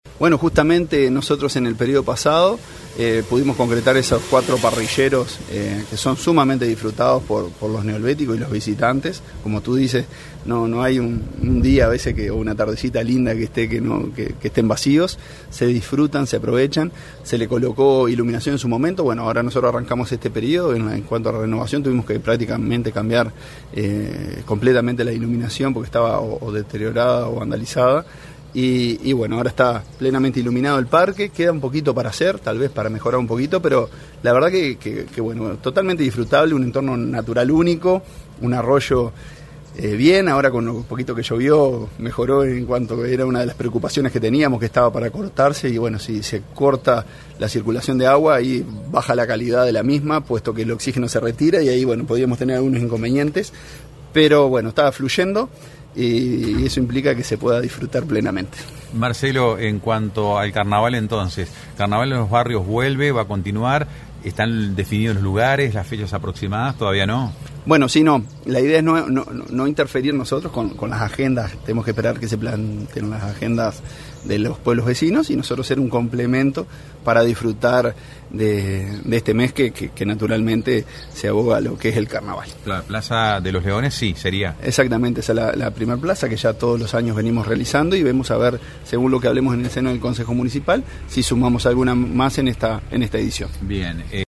El alcalde Marcelo Alonso destacó que, tras enfrentar problemas de deterioro y vandalismo en el sistema lumínico, se procedió a una intervención integral para garantizar la seguridad y el disfrute nocturno del entorno natural. Sobre estas mejoras y la vigencia de este espacio emblemático, escuchamos al jerarca municipal.